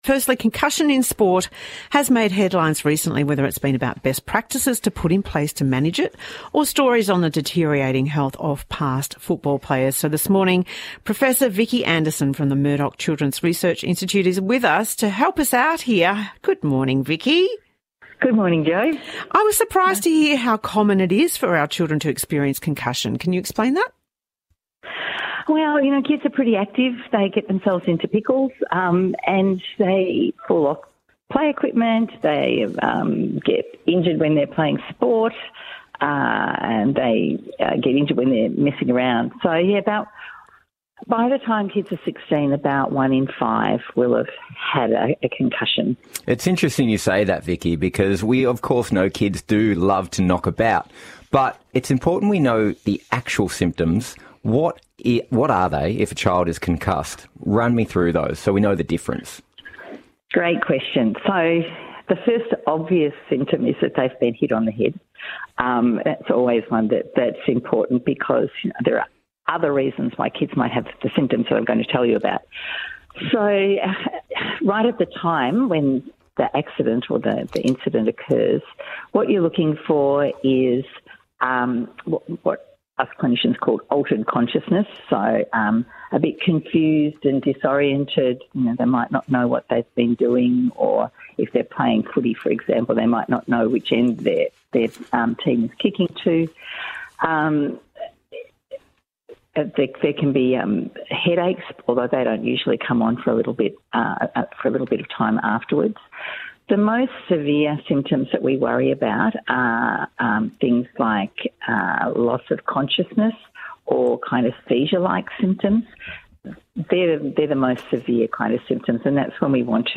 interview about childhood concussion on The House of Wellness Radio show